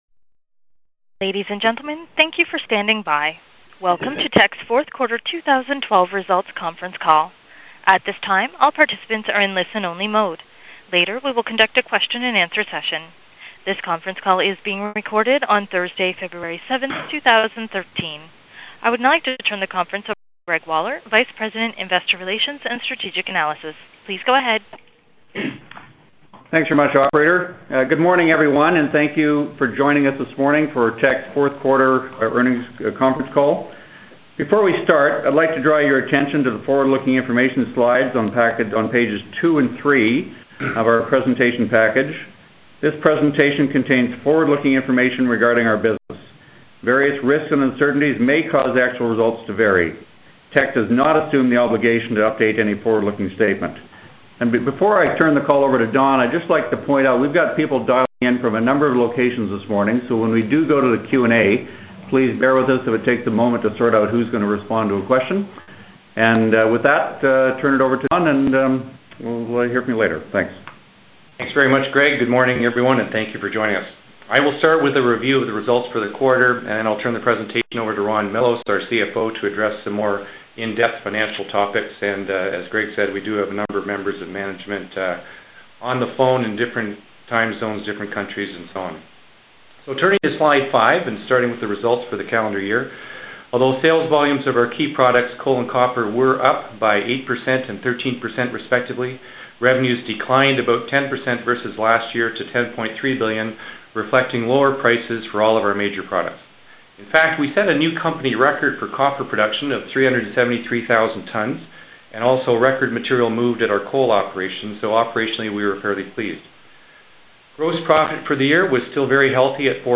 Q2 2012 Financial Report [PDF - 0.90 MB] Q2 2012 Financial Report Conference Call Presentation Slides [PDF - 0.72 MB] Q2 2012 Financial Report Conference Call Transcript [PDF - 0.29 MB] Q2 2012 Financial Report Conference Call Audio File [ - 24.81 MB]